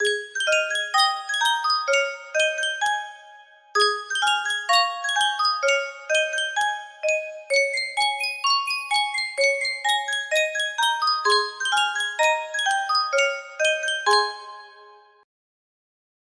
Sankyo Music Box - All Through the Night SVA music box melody
Sankyo Music Box - All Through the Night SVA
Full range 60